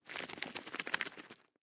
Звуки коктейля